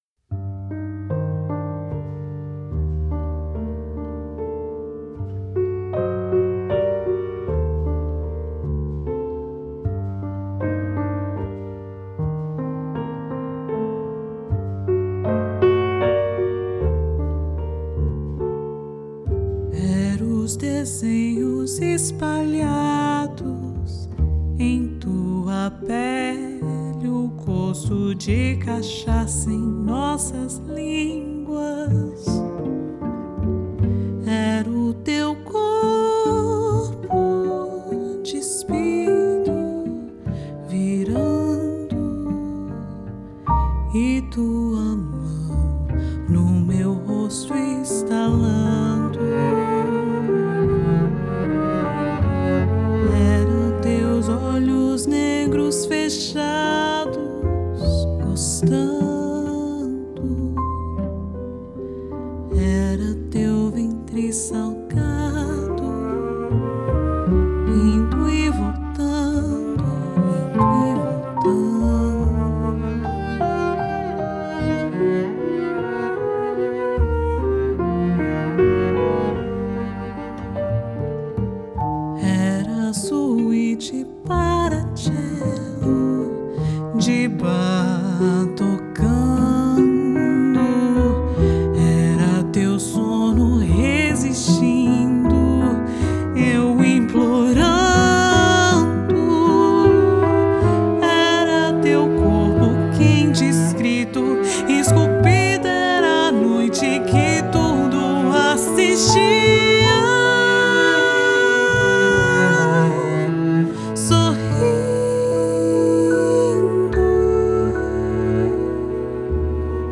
One a modern jazz composition, the other a sultry art song: